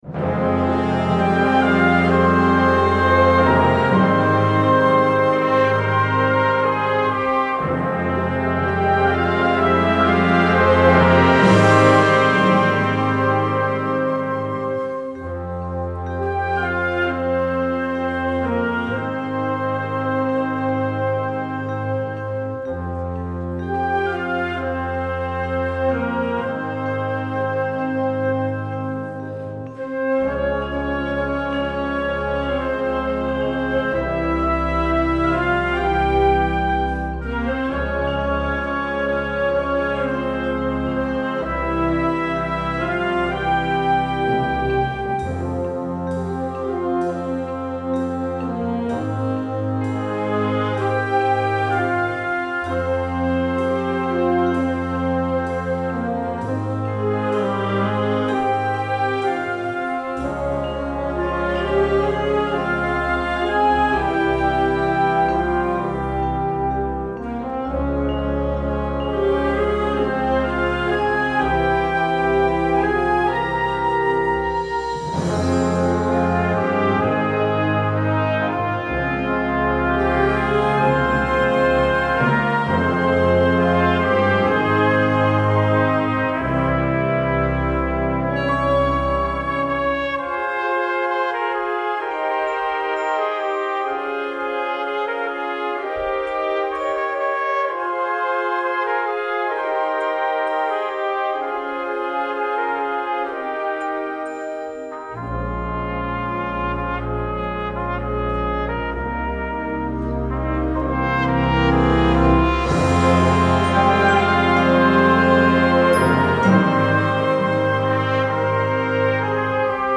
Gattung: Filmmusik für Jugendblasorchester
Besetzung: Blasorchester